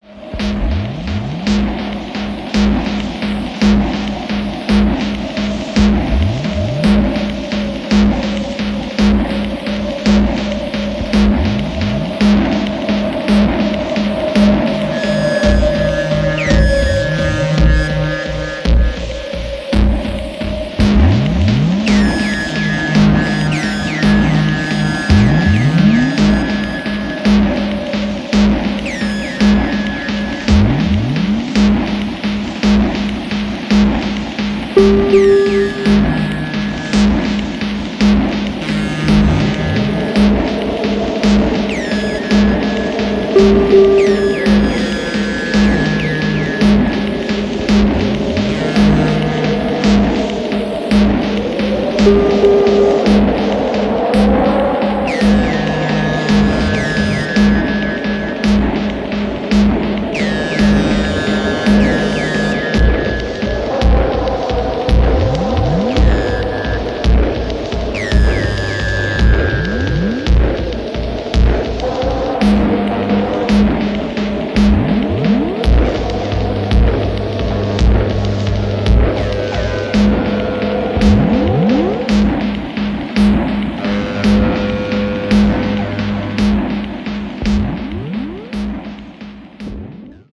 UK Industrial group
synthesziers, keyboards, percussion and vocals
guitar
piano, tape loops